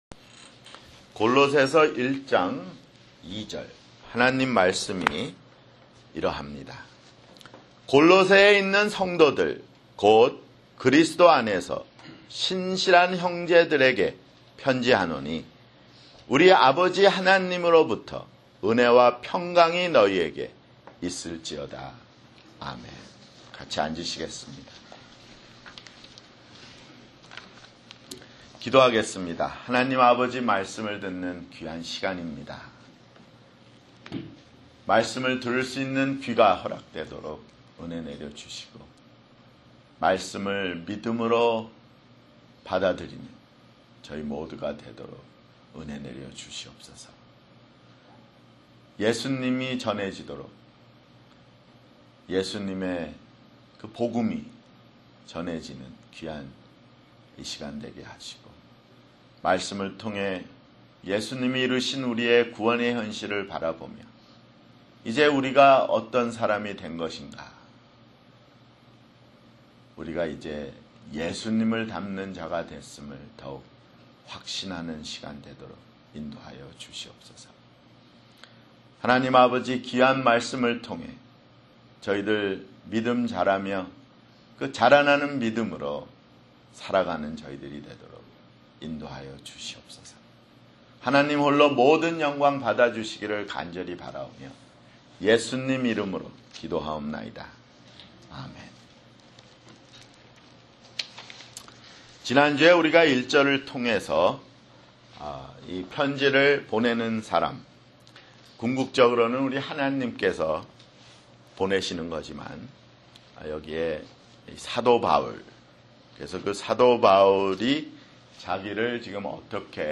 [주일설교] 골로새서 (3)